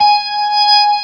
55O-ORG20-G#.wav